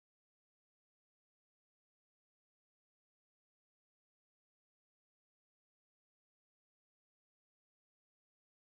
Kindertänze: Mariechen auf dem Stein
Tonart: D-Dur
Taktart: 2/4
Tonumfang: große Sexte